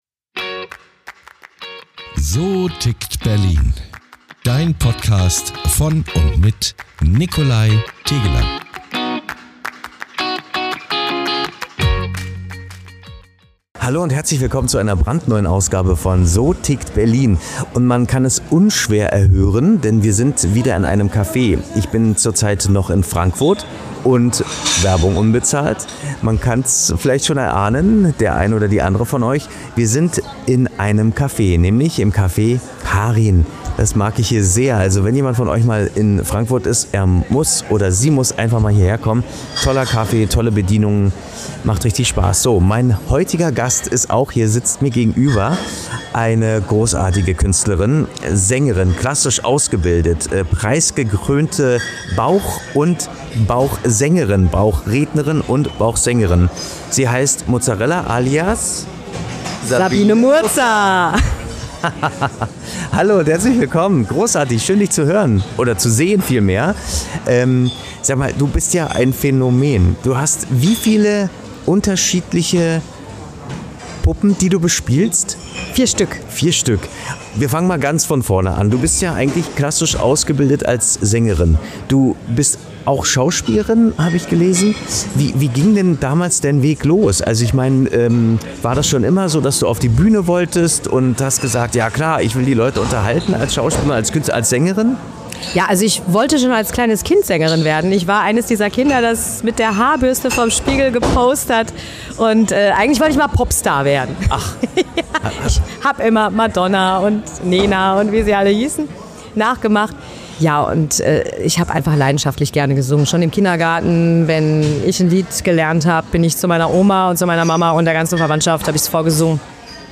Es geht um Mut zur Veränderung, um künstlerische Freiheit – und um die Frage, warum Humor und Musik manchmal genau dann am besten funktionieren, wenn man sie einfach verbindet. Eine Folge voller Überraschungen, Stimmen und echter Bühnenleidenschaft.